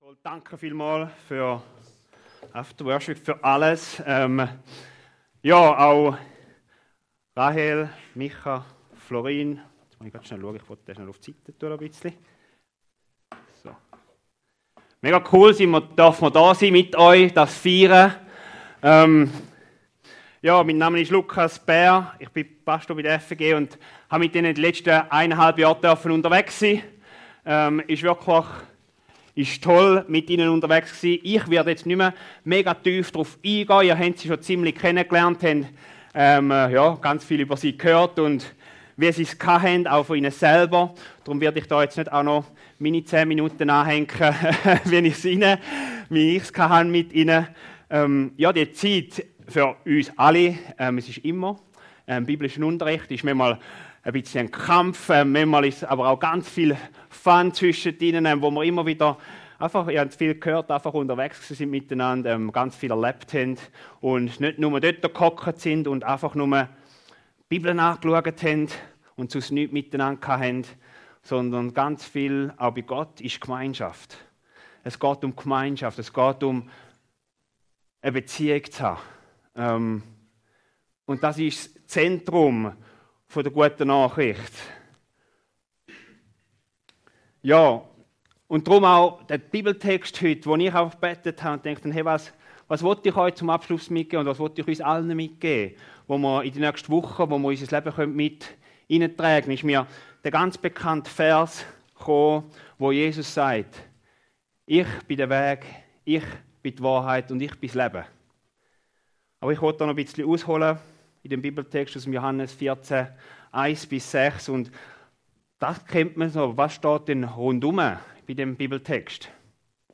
Predigt zum Untiabschluss 2024